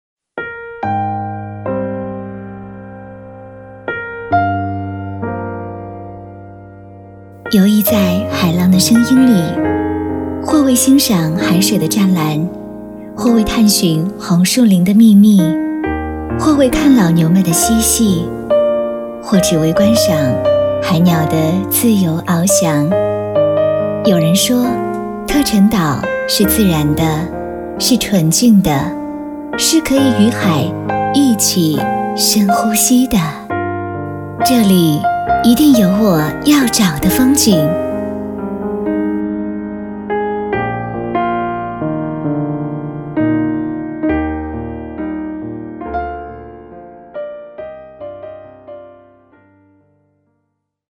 女声配音
内心独白女国76